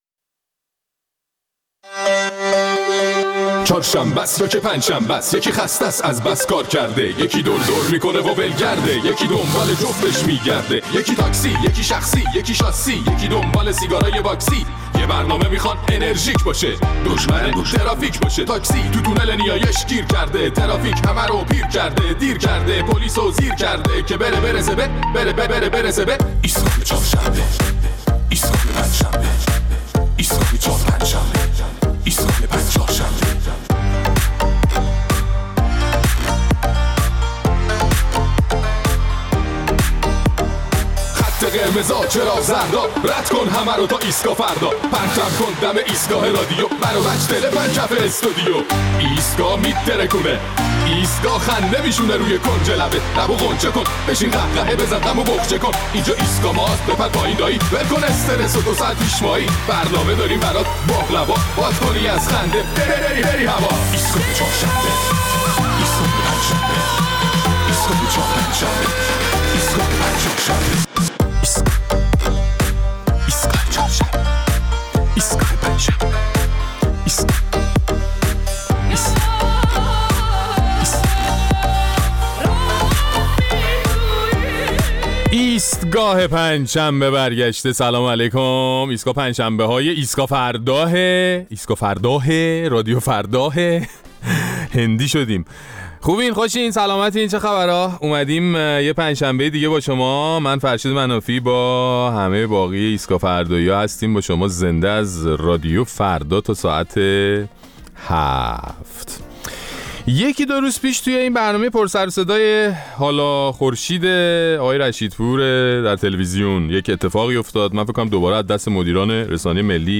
در این برنامه ادامه نظرات شما را در مورد پدیده «کلی‌خری» بعضی از مردم و هجوم بعضی از هموطنان به فروشگا‌ه‌ها برای خریدن اقلام مختلف می‌شنویم.